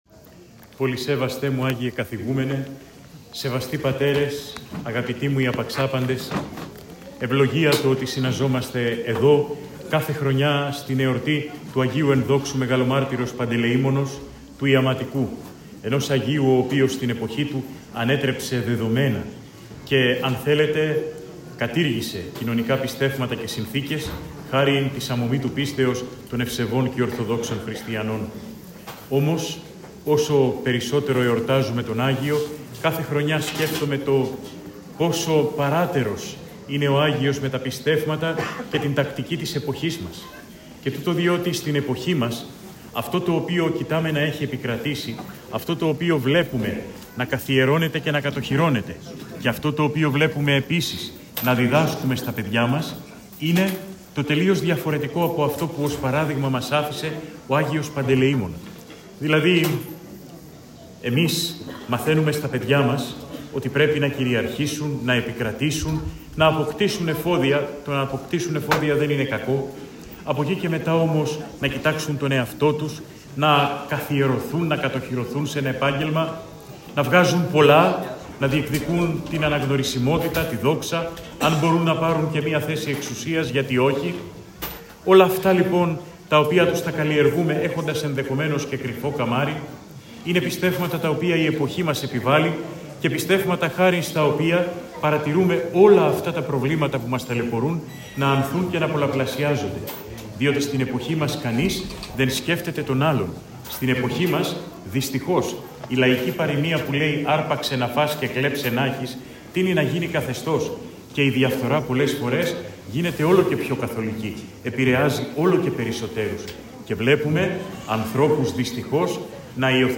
Η πανήγυρη της Ιεράς Μονής Αγίου Παντελεήμονος Αγιάς - Ορθοδοξία News Agency
Με την δέουσα λαμπρότητα τιμήθηκε και φέτος η μνήμη του Αγίου Μεγαλομάρτυρος Παντελεήμονος του Ιαματικού στην ομώνυμη Ιερά Μονή στην Αγιά Λάρισας.